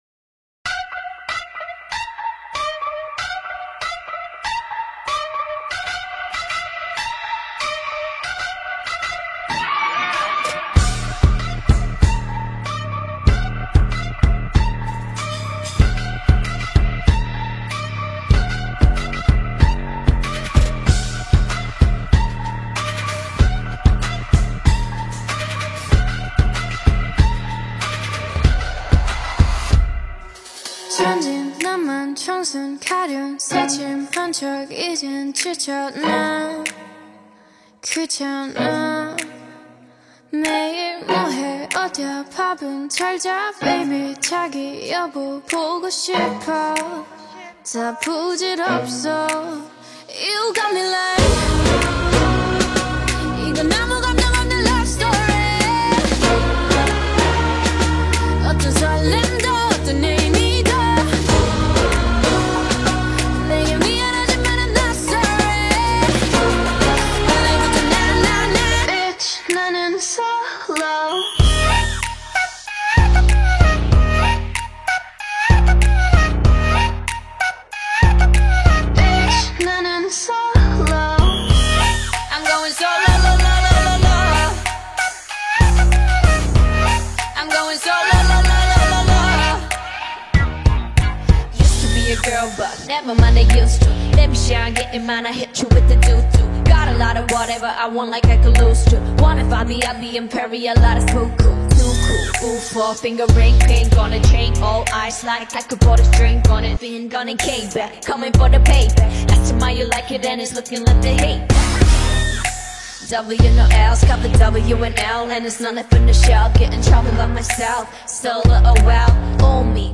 Азиатские хиты